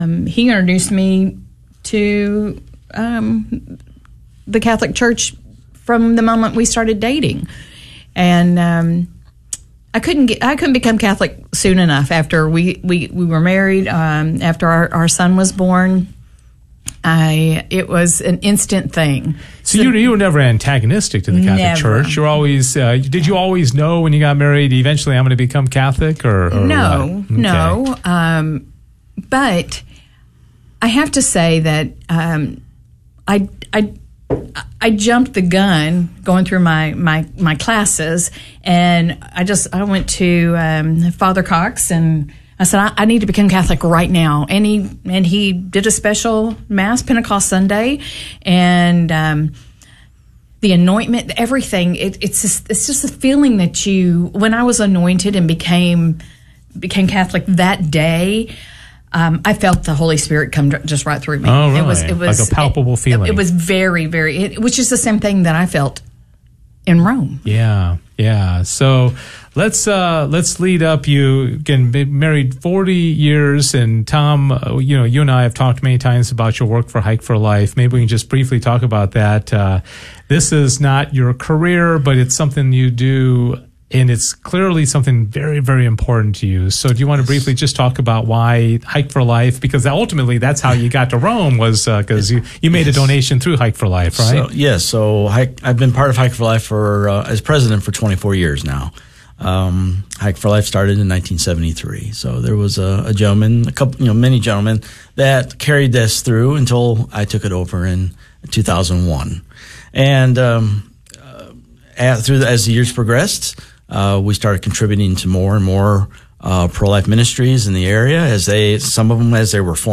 KATH Interview of the Week